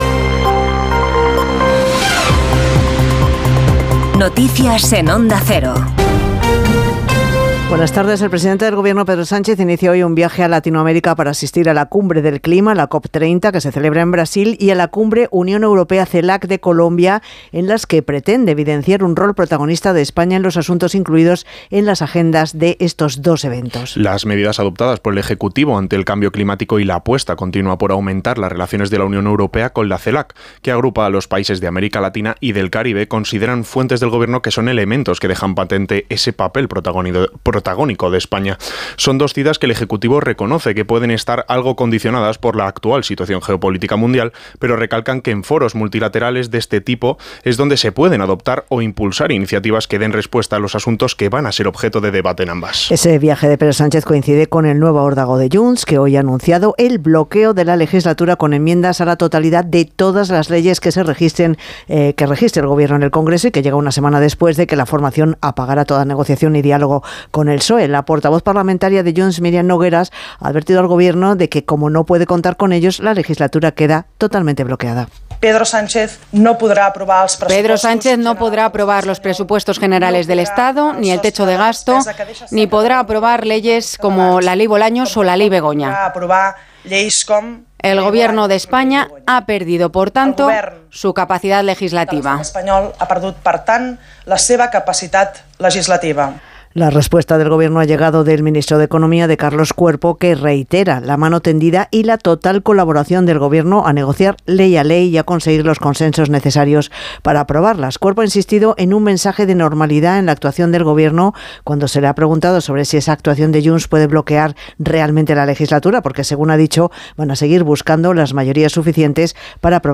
Las noticias en Onda Cero